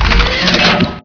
rocket_load.wav